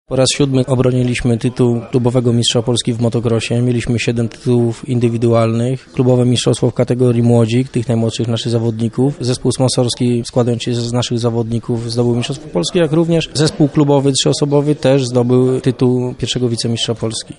Lubelska scena motocrossowa odniosła w zeszłym sezonie wiele znaczących sukcesów.